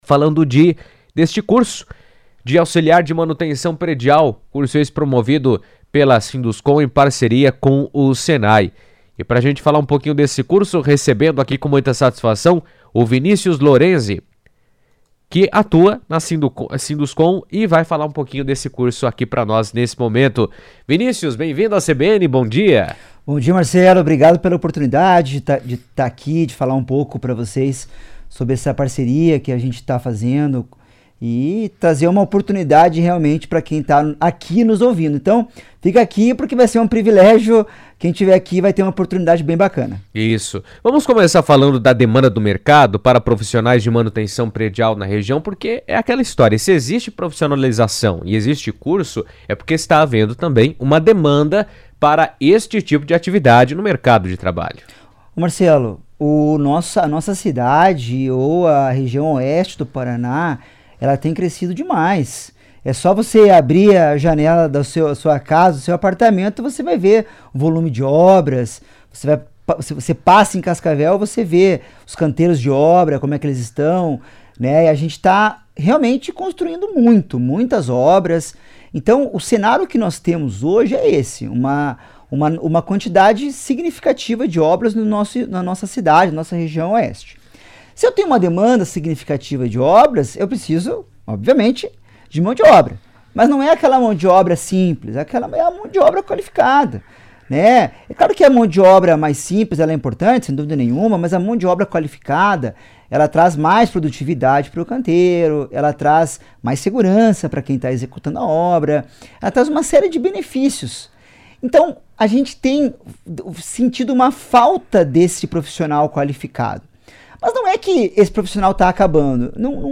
O Sinduscon, em parceria com o Senai Paraná, promoverá o curso de auxiliar de manutenção predial, que será realizado em Cascavel, Toledo e Foz do Iguaçu. A iniciativa busca capacitar profissionais para atender às demandas da construção civil e ampliar as oportunidades de inserção no mercado de trabalho. Em entrevista à CBN